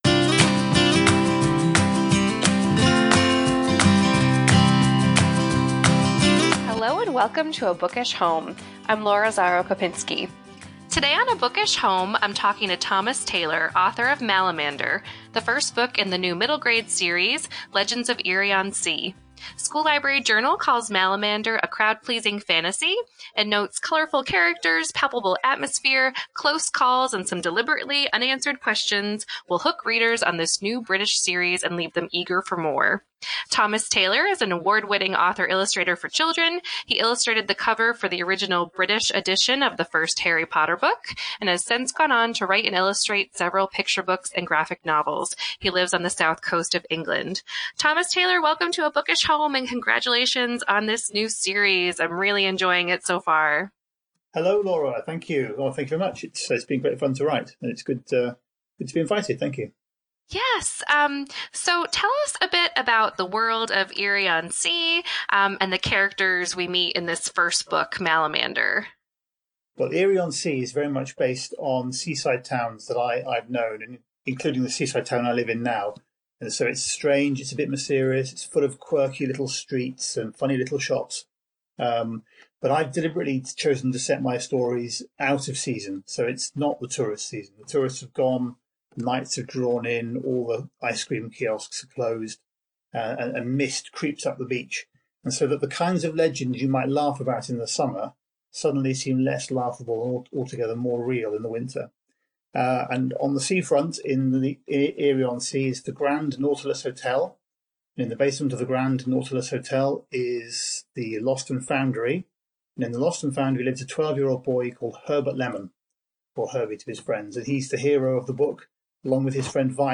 This week, I’m chatting with Thomas Taylor, author of Malamander, the first book in the new middle-grade series, Legends of Eerie-On-Sea.